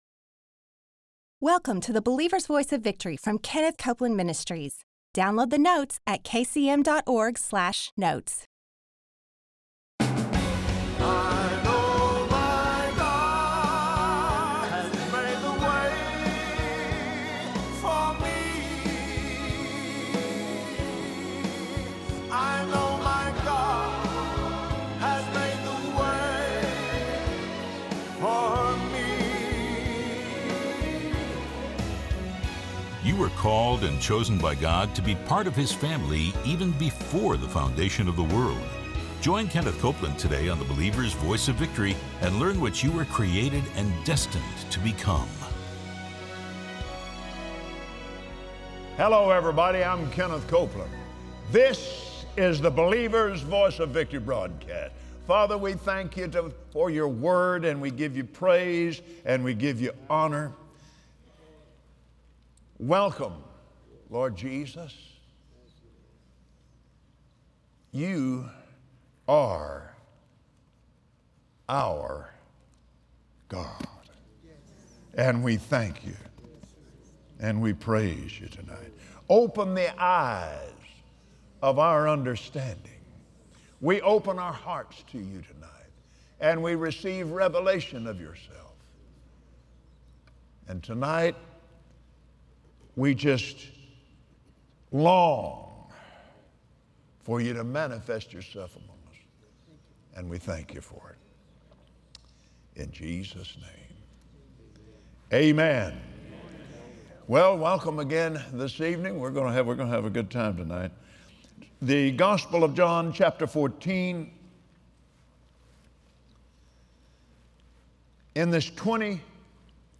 Watch Kenneth Copeland on Believer’s Voice of Victory share how, from the very beginning, you were created by Love to live a blessed and abundant life in the earth.